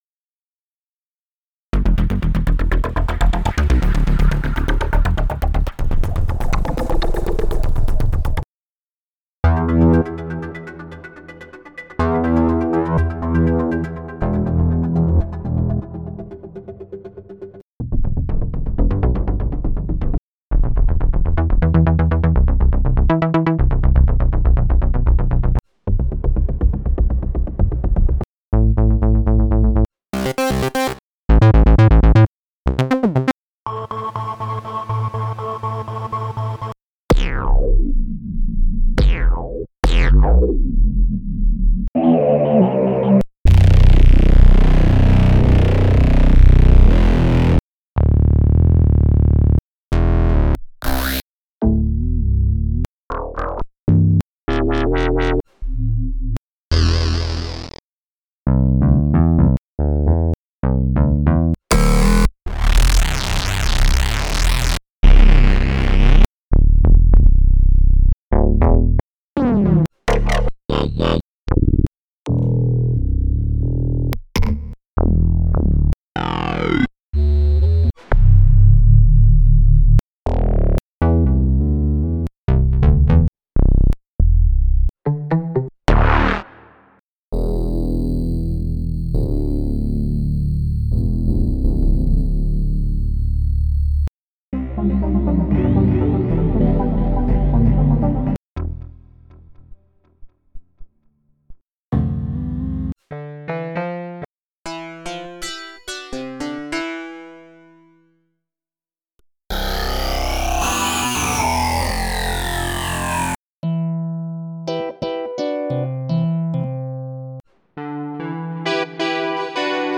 Получил последнюю версию, вот потыкал наугад ..нравится...Когда нужен звук, который хорошо знаком, но на Серумах его нету, то в этом синте есть. Т е не совсем старье, а немного с запаздыванием моды, но огромное количество и отличного качества.